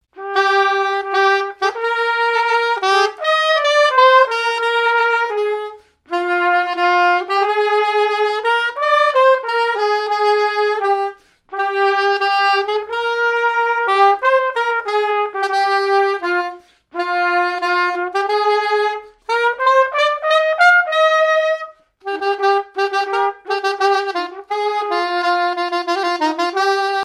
fiançaille, noce
répertoire de marches de noces
Pièce musicale inédite